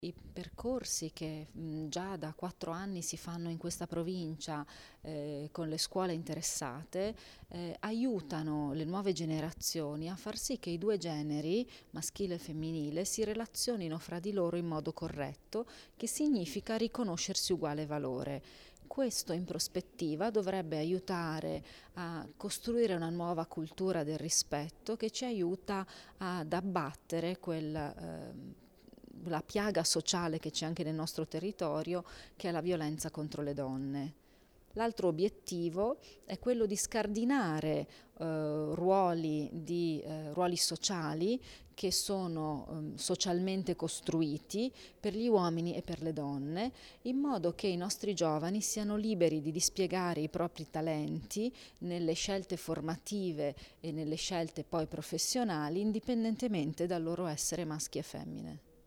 int_Ferrari_1_dicembre_MP3_256K.mp3